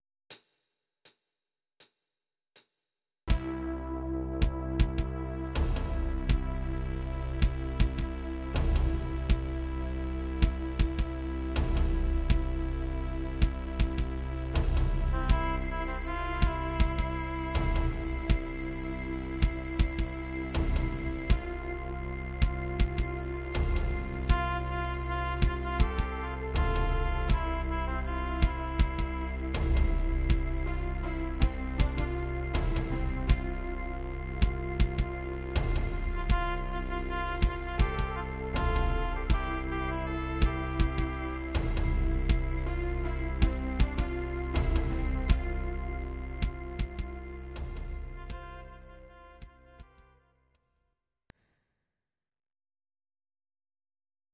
Audio Recordings based on Midi-files
Instrumental, 1980s